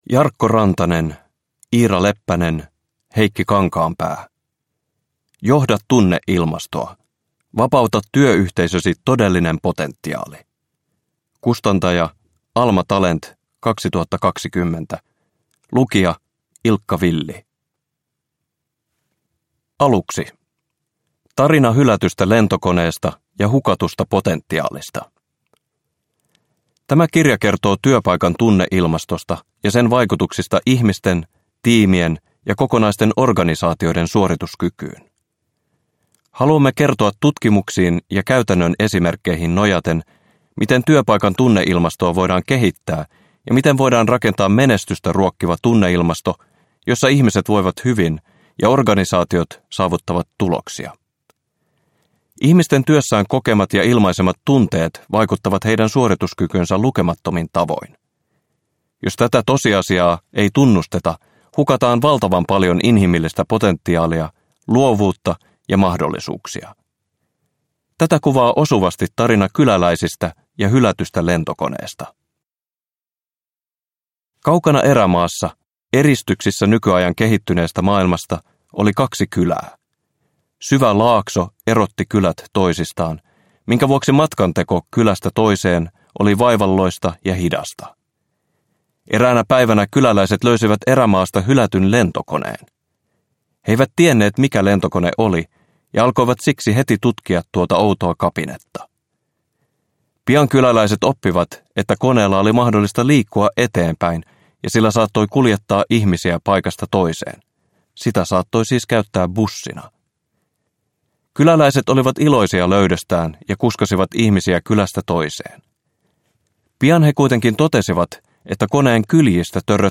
Uppläsare: Ilkka Villi